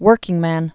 (wûrkĭng-măn)